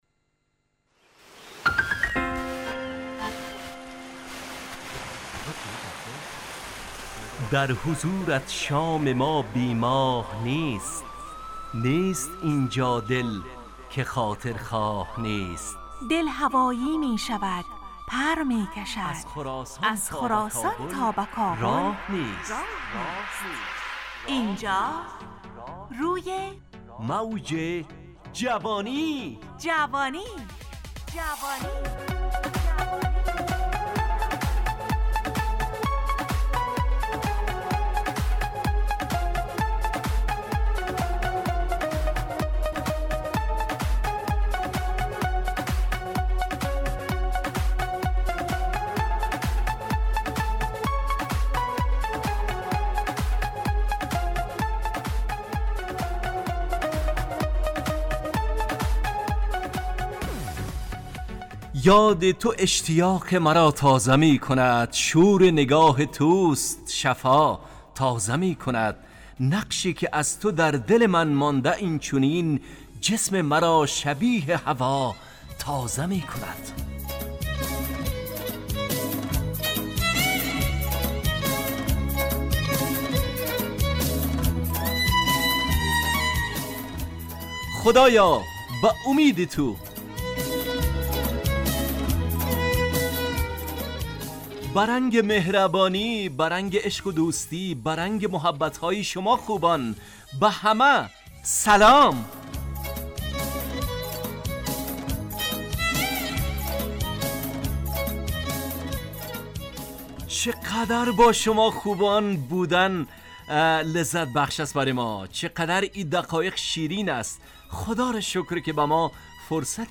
همراه با ترانه و موسیقی مدت برنامه 55 دقیقه .